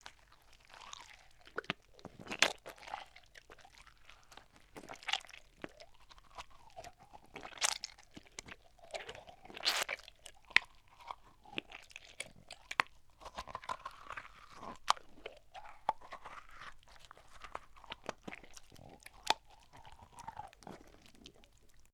horror
Horror Eating Flesh